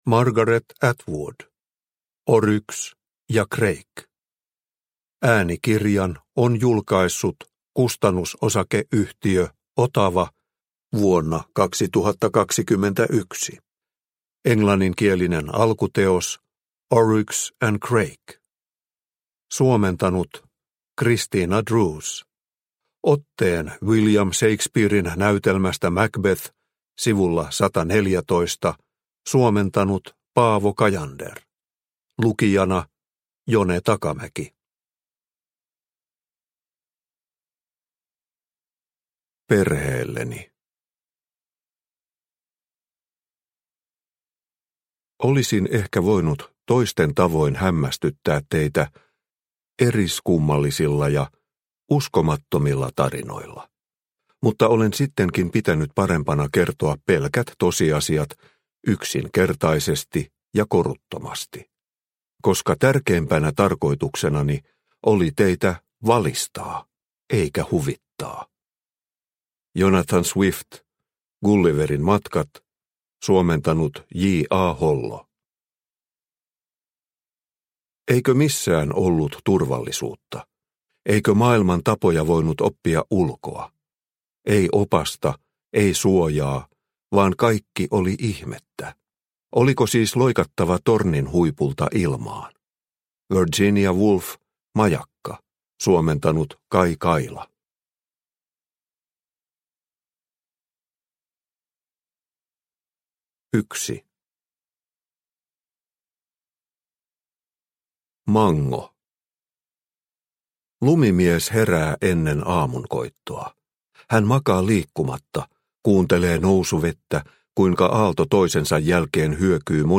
Oryx ja Crake – Ljudbok – Laddas ner